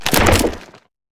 creaking_attack1.ogg